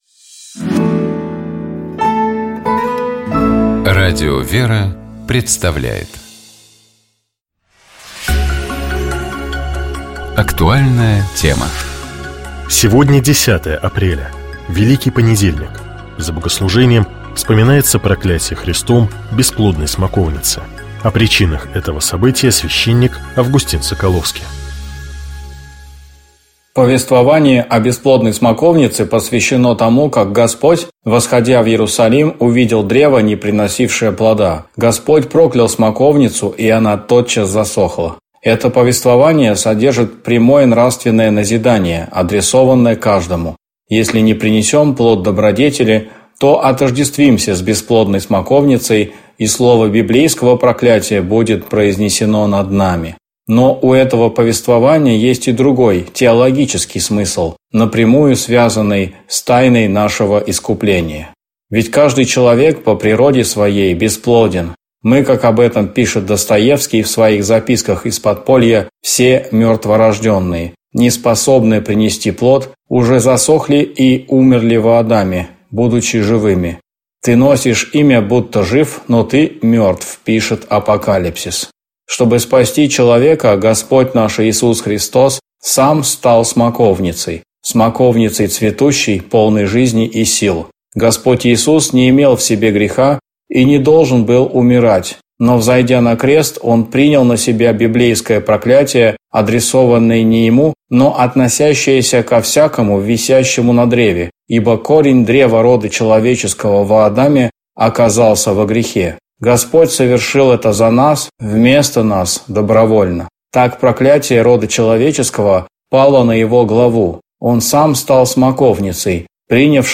О причинах этого события, — священник